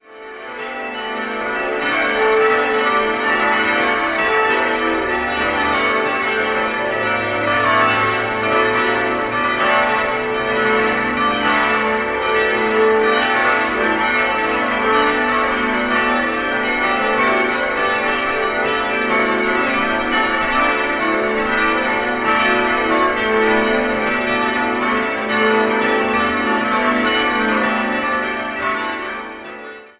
Im schlichten Innenraum sind vor allem die bunten Glasfenster sehenswert. 7-stimmiges Geläut: a'-h'-cis''-d''-e''-fis''-g'' Die Glocken wurden im Jahr 1967 von der Gießerei F. W. Schilling in Heidelberg hergestellt.